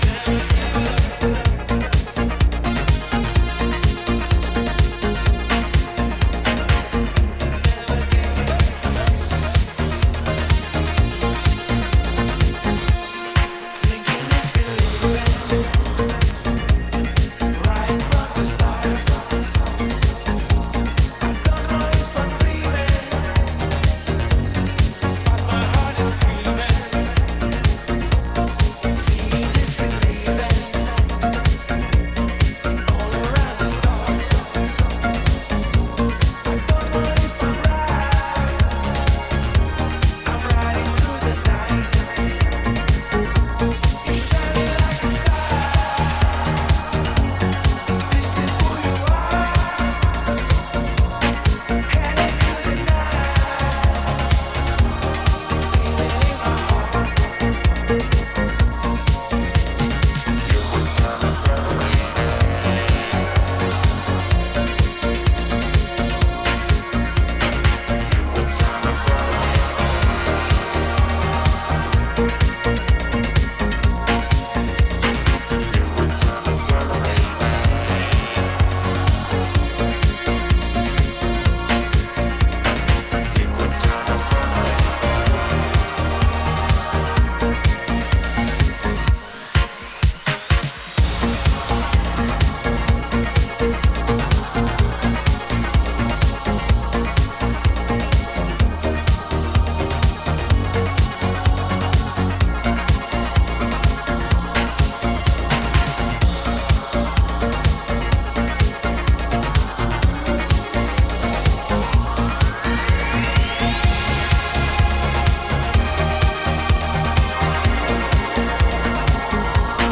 アナログシンセの軽快なメロディーや歯切れの良いパーカッション、ヴォコーダー・ヴォイスが壮大なフックへと展開する